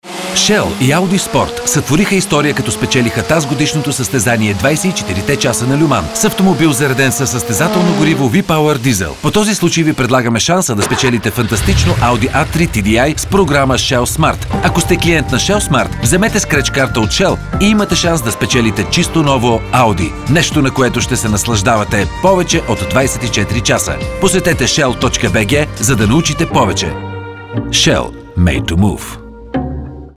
Sprecher bulgarisch für TV / Rundfunk / Industrie.
Sprechprobe: Werbung (Muttersprache):
Professionell voice over artist from Bulgary.